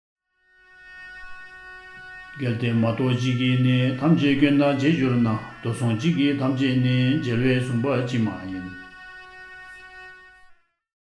(CSV import BCA tib chanted verse data import)